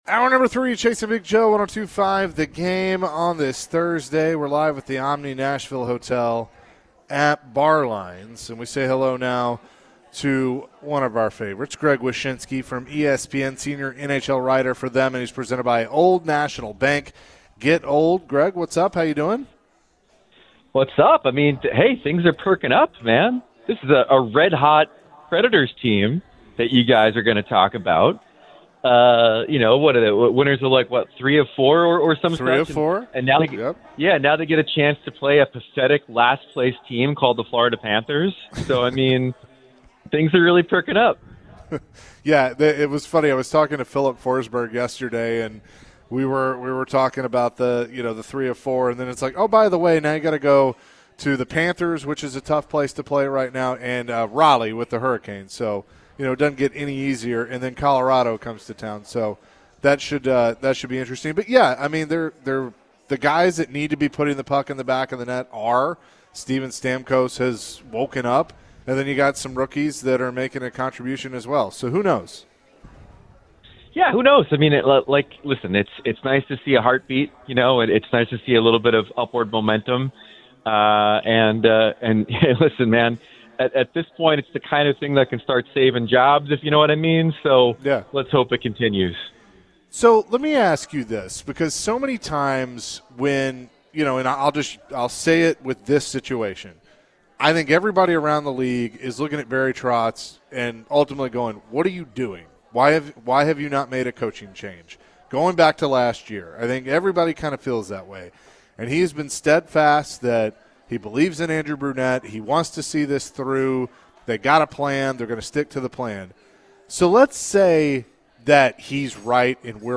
The guys were joined by ESPN NHL Senior Writer Greg Wyshynski on the show and shared his thoughts on the news around the NHL and the Nashville Predators. Are the Preds on the upswing?